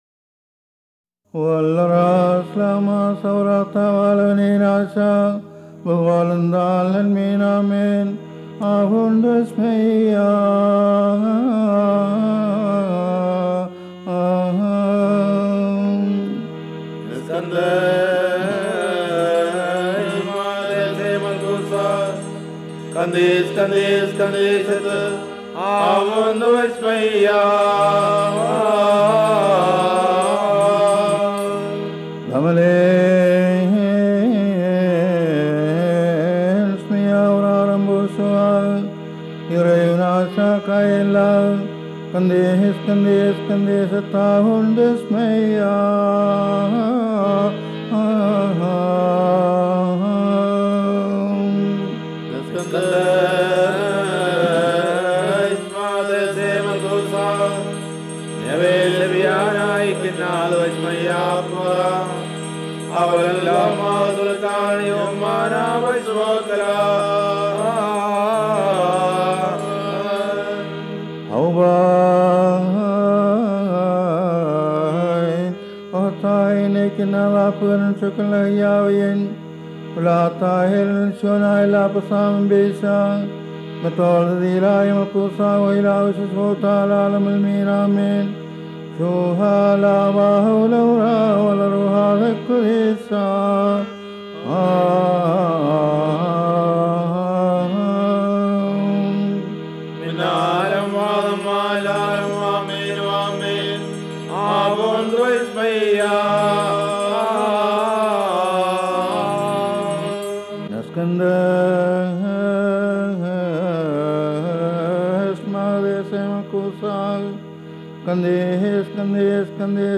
Harmonium
Triangle